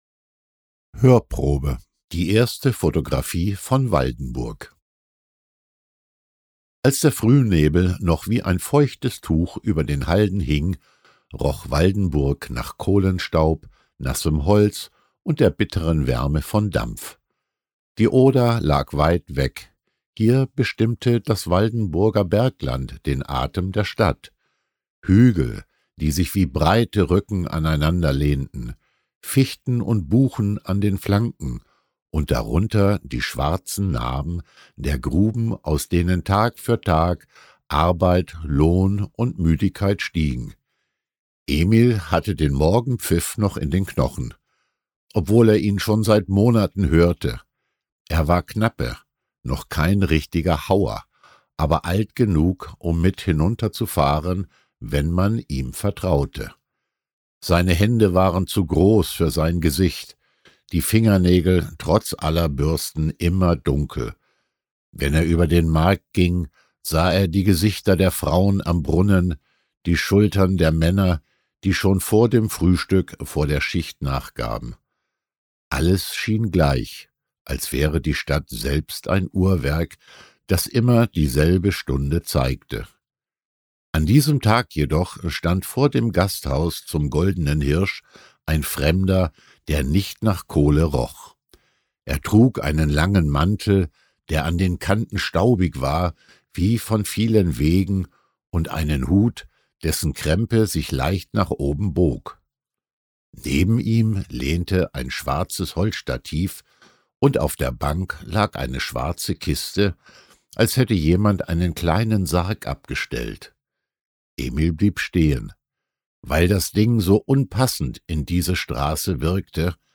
Heimatgeschichten aus Schlesien – Hörbuch
Eine Lesung, die Erinnerungen weckt – auch dort, wo man selbst nie gelebt hat.
Beim Zuhören spürt man den Staub der Wege, hört das Läuten der Kirchenglocken, riecht das frisch geschnittene Getreide.
Die Geschichten sind ruhig und atmosphärisch, zugleich spannend und lebensnah.
Ein stilles, atmosphärisches Erlebnis voller Wärme und Tiefe.